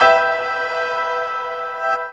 PIANOREVRB-R.wav